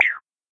add jump sound
Jump.ogg